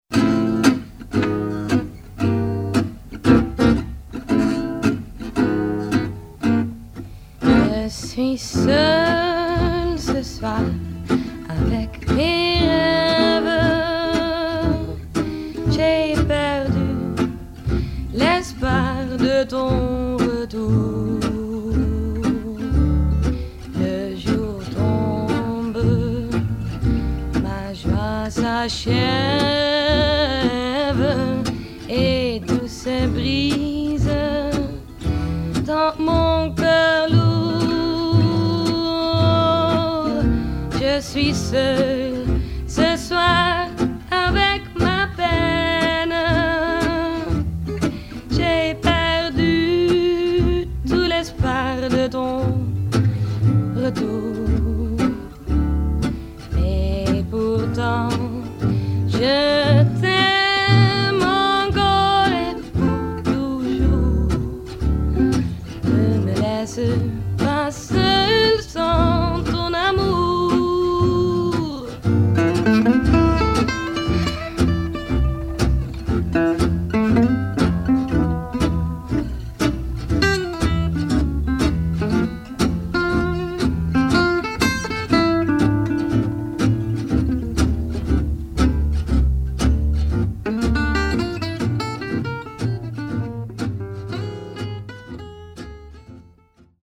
Vocal recorded with 74B Jr